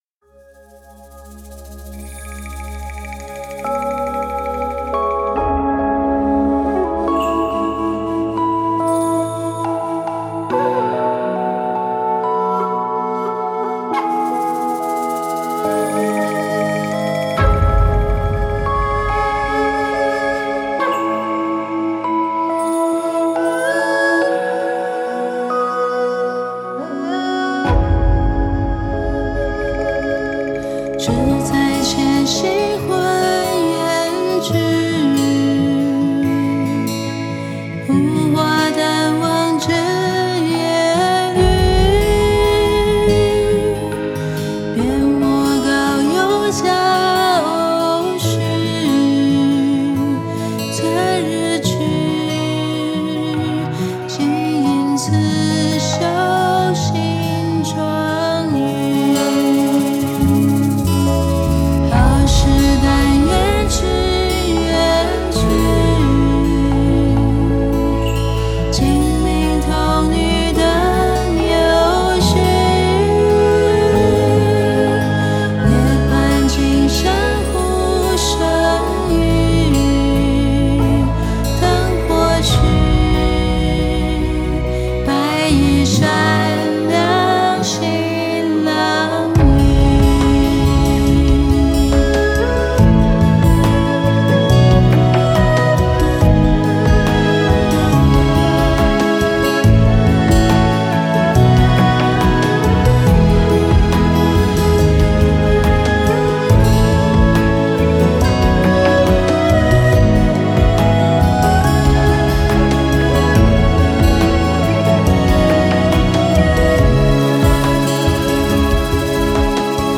二胡